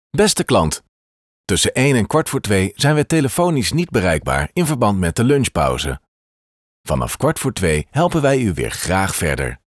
Commerciale, Profonde, Amicale, Chaude, Corporative
Téléphonie